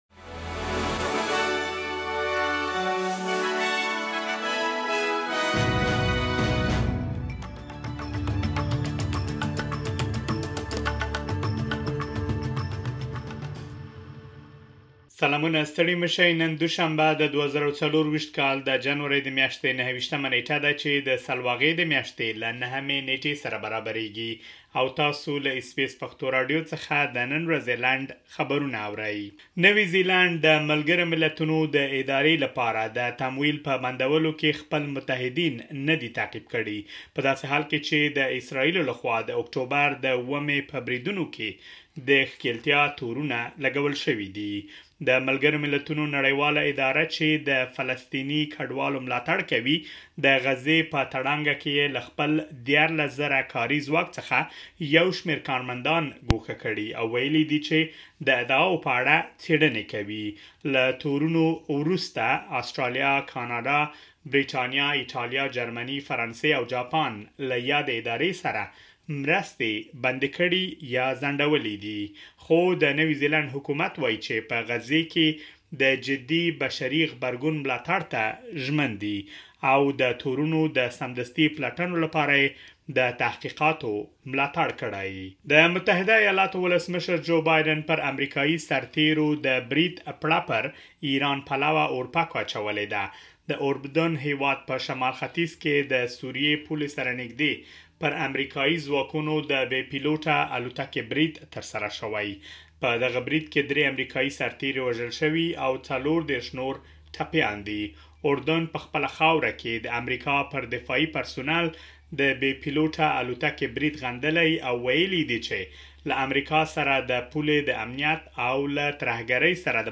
د اس بي اس پښتو راډیو د نن ورځې لنډ خبرونه |۲۹ جنوري ۲۰۲۴